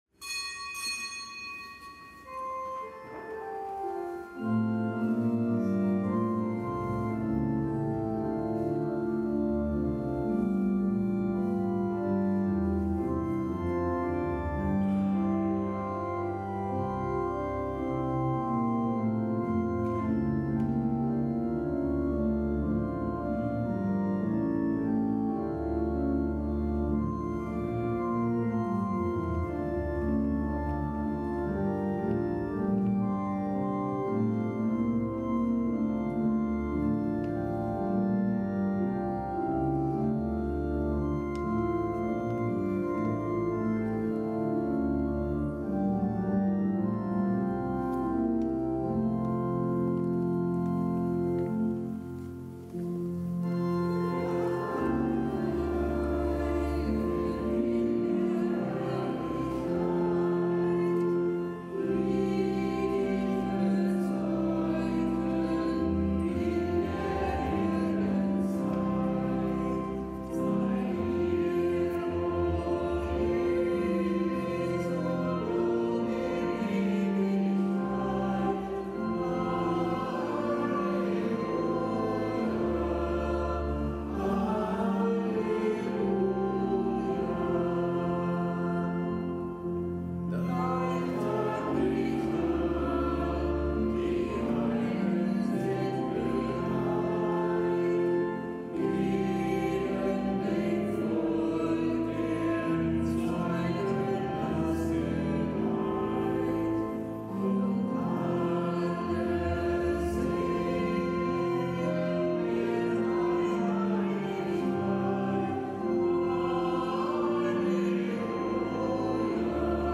Kapitelsmesse aus dem Kölner Dom am Donnerstag der achtundzwanzigsten Woche im Jahreskreis, Nichtgebotener Gedenktag der Heiligen Hedwig von Andechs, Herzogin von Schlesien und des Heiligen Gallus,
Zelebrant: Weihbischof Dominikus Schwaderlapp.